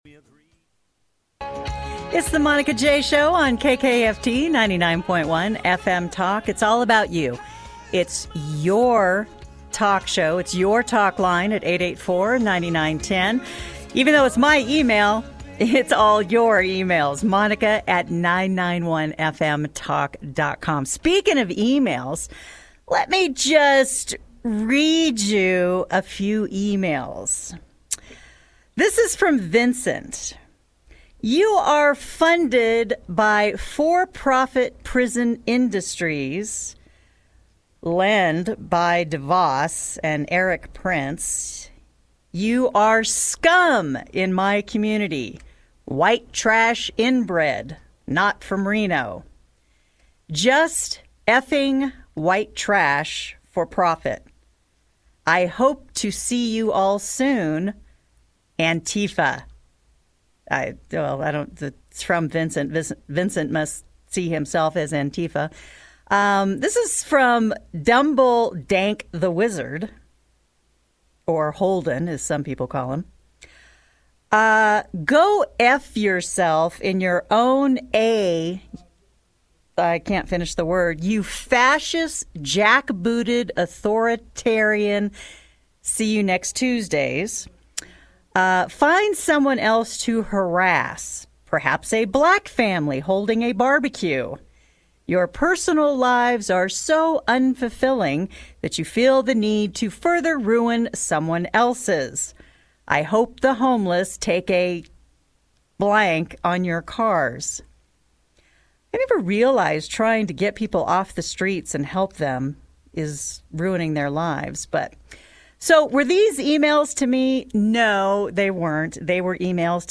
Live fron Carson City.